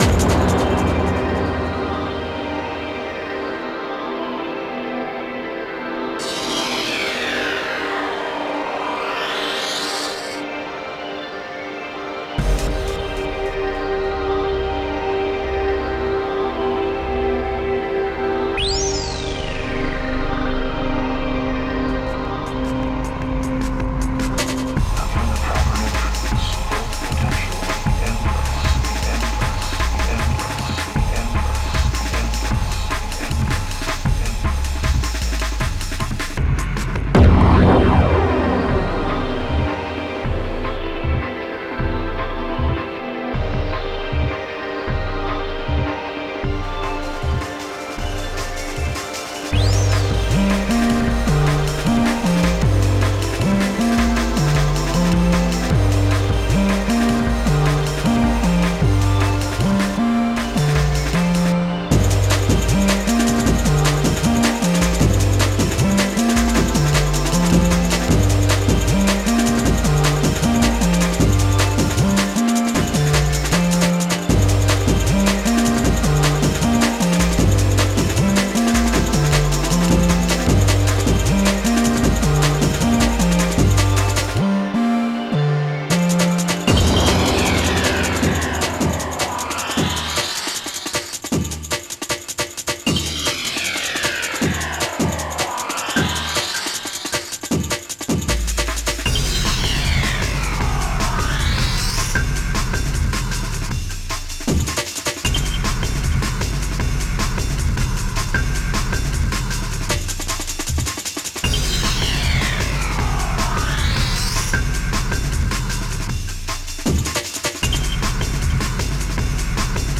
you've gotten really good at mixing, the instruments are clear and drums hit just just right